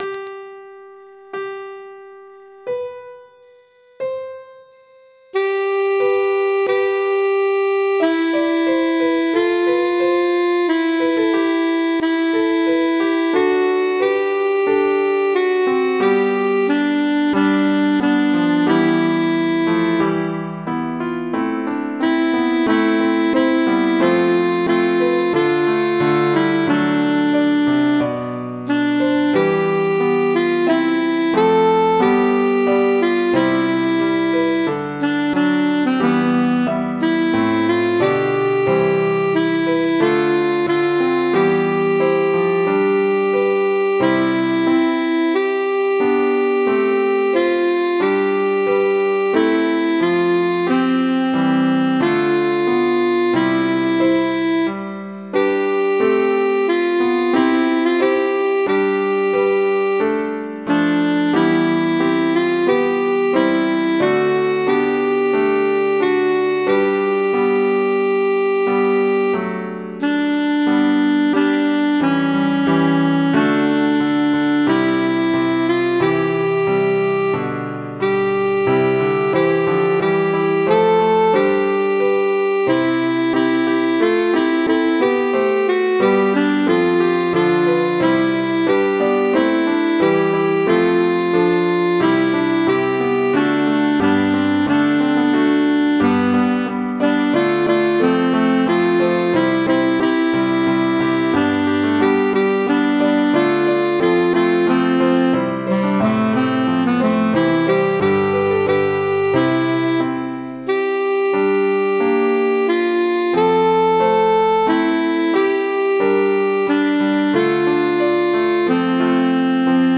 Tulerunt Dominum meum-alto.mp3